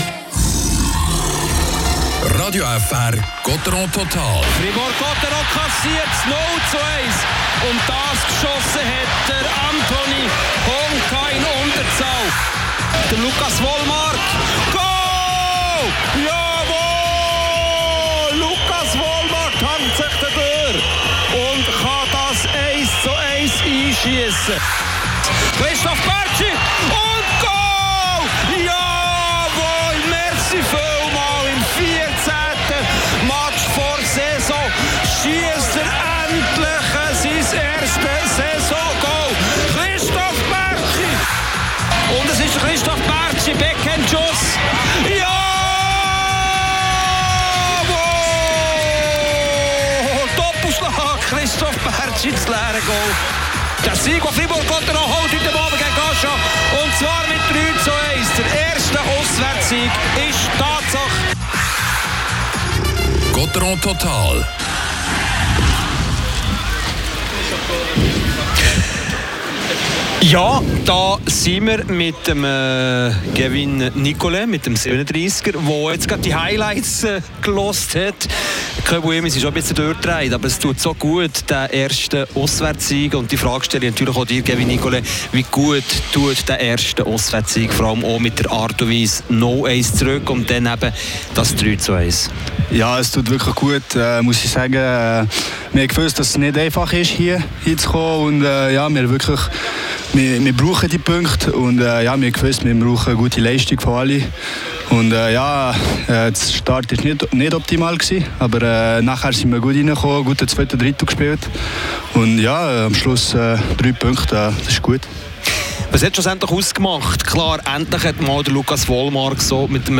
und das Interview zum ersten Saisontor von Christoph Bertschy.